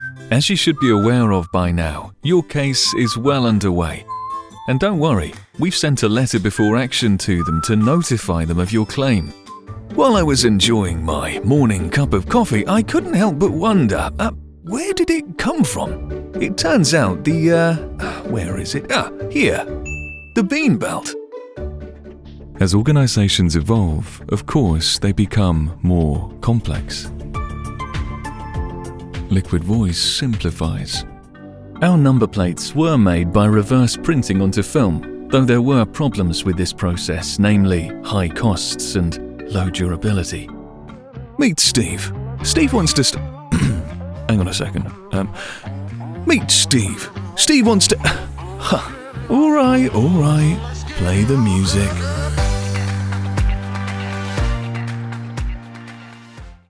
Explainer & Whiteboard Video Voice Overs | Instructional YouTube Voice Over | Male & Female
The sound of my voice is dynamic, modulated, warm, uplifting, and enthusiastic. I also have the ability to project a calming or soothing effect.